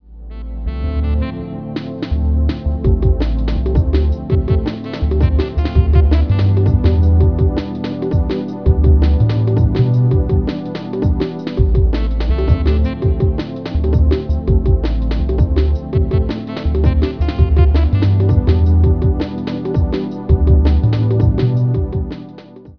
Mellow vibes for a laid back generation